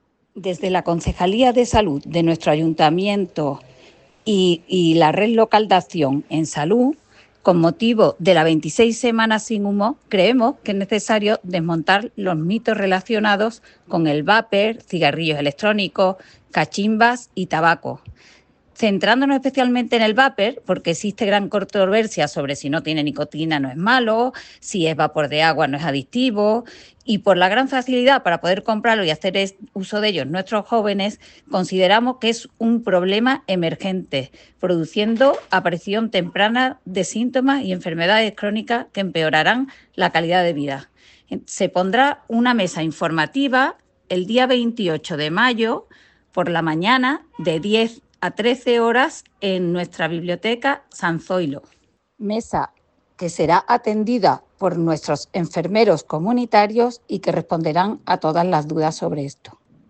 La concejal delegada de Salud, María Enrile, ha señalado que el objetivo de esta iniciativa es “desmontar los mitos relacionados con el vapeo, cigarrillos electrónicos, cachimbas y tabaco”, haciendo especial hincapié en los nuevos dispositivos como el vaper, cuyo uso se ha incrementado notablemente entre los más jóvenes.
Cortes de voz